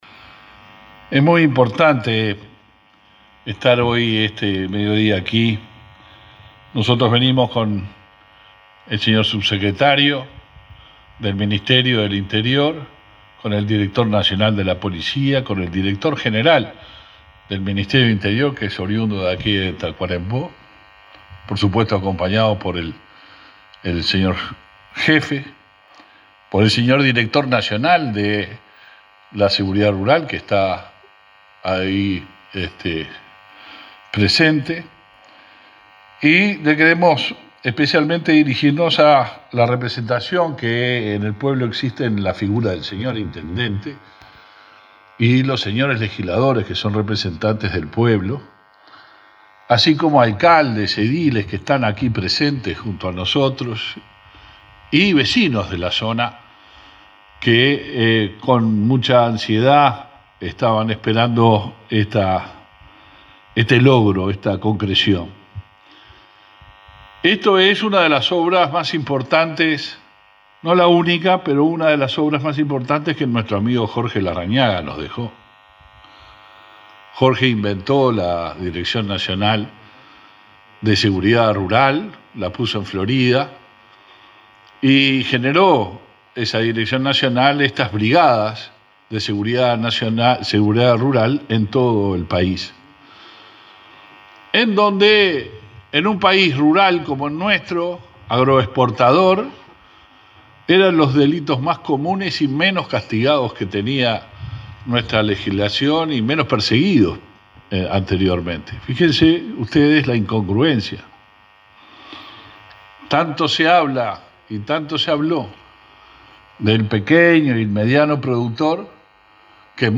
Palabras del ministro del Interior, Luis Alberto Heber
Palabras del ministro del Interior, Luis Alberto Heber 27/08/2021 Compartir Facebook X Copiar enlace WhatsApp LinkedIn Este viernes 27, el ministro del Interior, Luis Alberto Heber, participó de la inauguración de la Brigada Departamental de Seguridad Rural, ubicada en el kilómetro 370 de la ruta 5, en la localidad de Batoví, departamento de Tacuarembó.